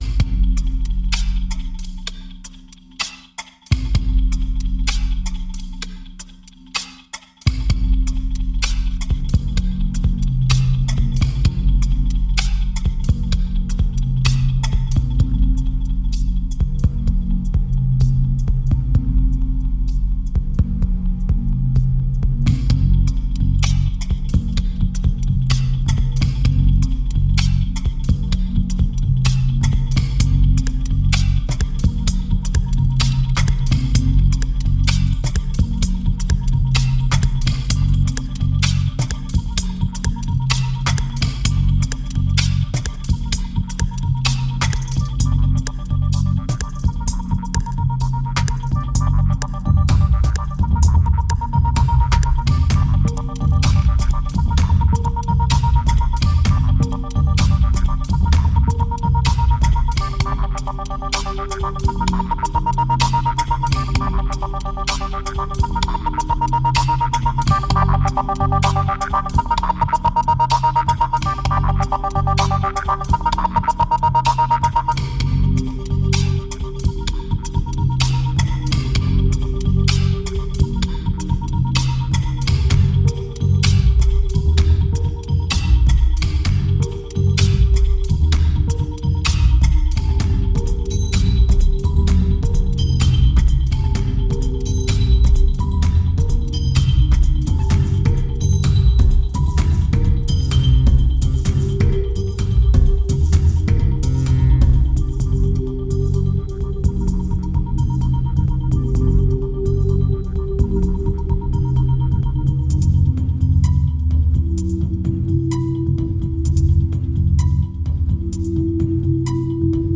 2277📈 - 39%🤔 - 128BPM🔊 - 2009-12-12📅 - -17🌟
Dubstep